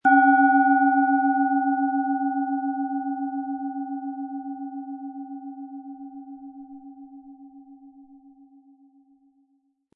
Planetenton 1
Es ist eine von Hand gearbeitete tibetanische Planetenschale Pluto.
Im Sound-Player - Jetzt reinhören hören Sie den Original-Ton dieser Schale.
MaterialBronze